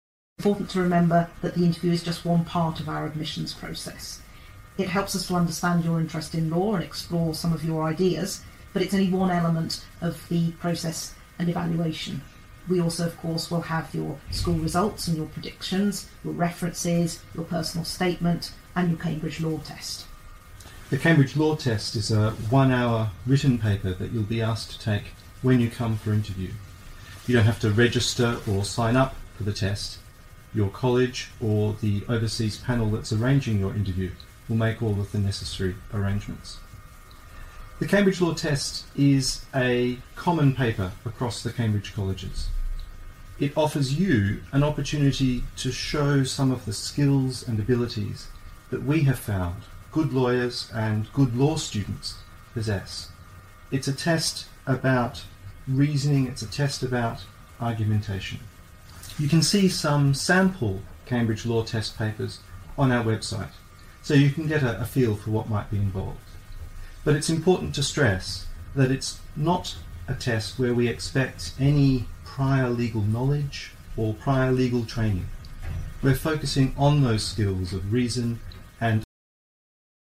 Sample: You will hear a recording of a lecture. At the end of the recording, the last word or group of words has been replaced by a beep.